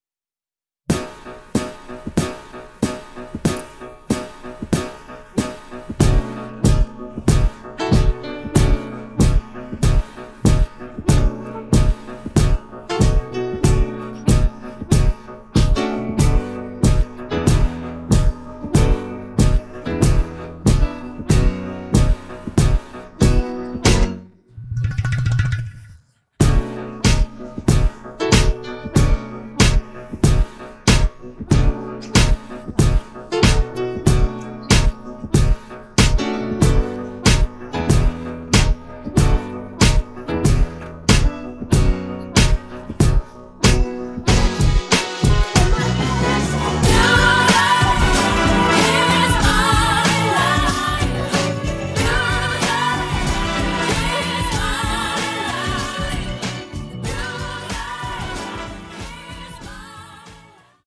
음정 원키 (코러스
장르 가요 구분